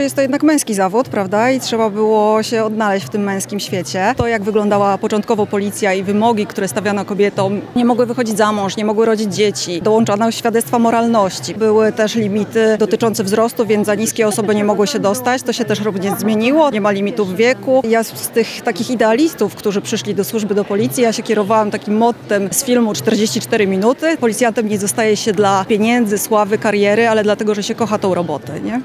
Gala stulecia kobiet w policji odbyła się w Centrum Spotkania Kultur w Lublinie.